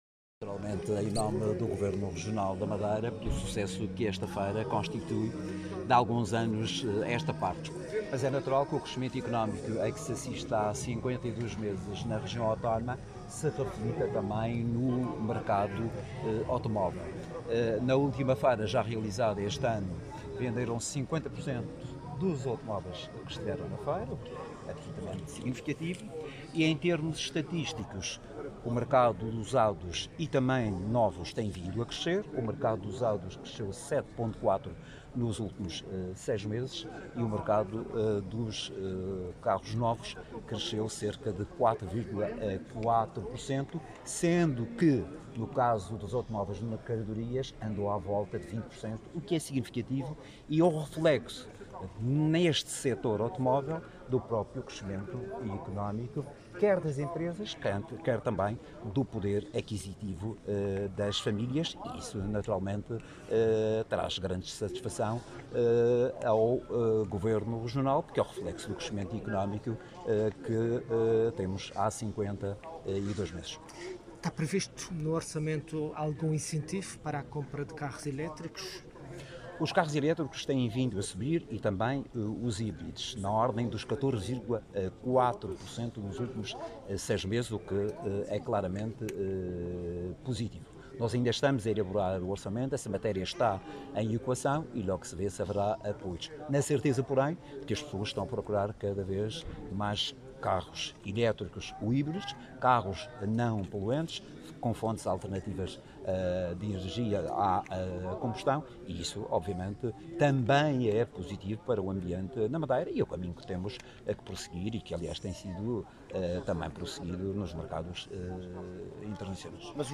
O Secretário Regional da Economia, José Manuel Rodrigues, destacou, esta quarta-feira, na abertura da segunda edição de 2025 do Mercado de Usados, no Madeira Tecnopolo, que o crescimento económico sustentado da Região Autónoma da Madeira está a refletir-se positivamente no setor automóvel, tanto no mercado de usados como no de novos veículos.